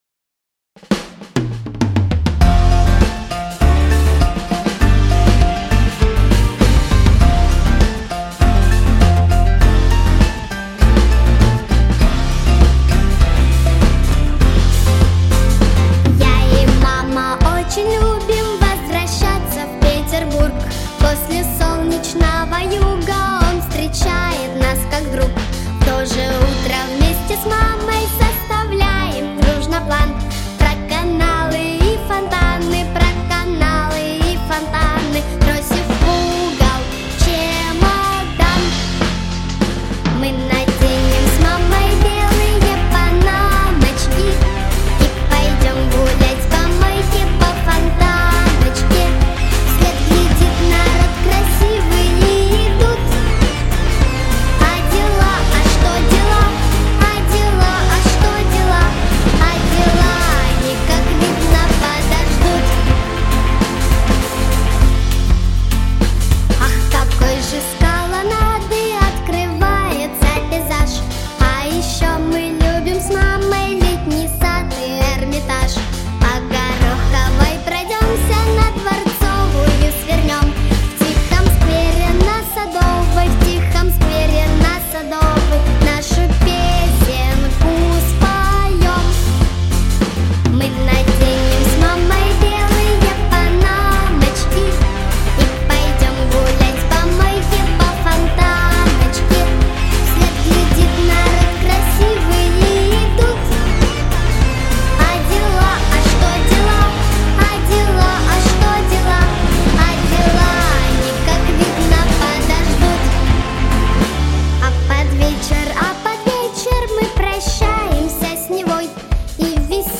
🎶 Детские песни / Песни про маму